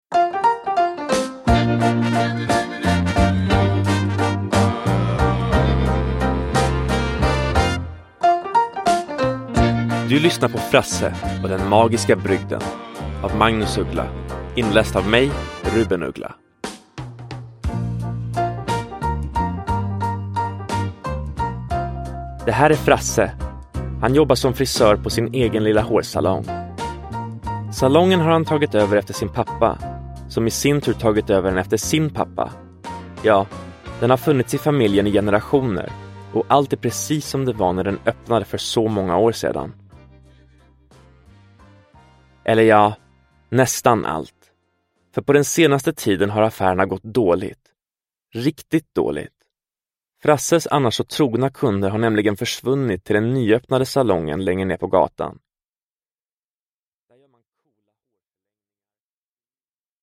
Frasse och den magiska brygden – Ljudbok – Laddas ner